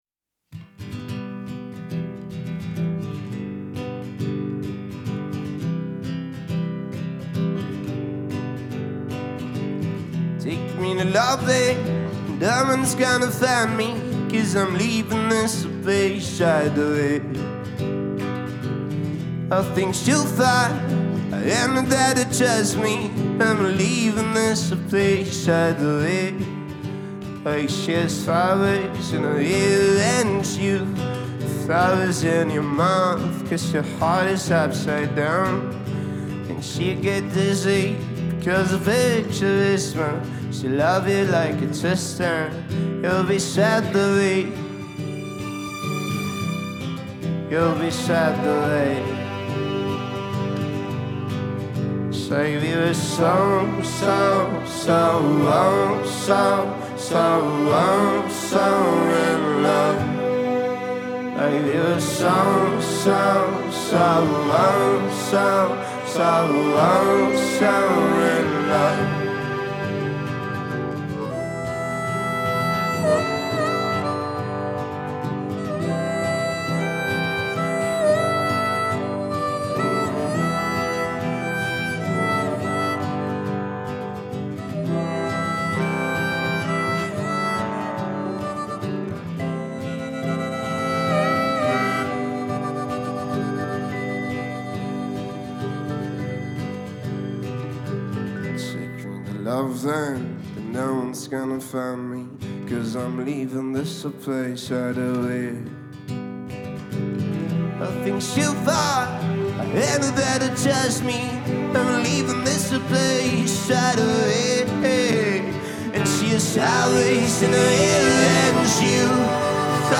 • Жанр: Pop